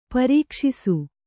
♪ parīkṣisu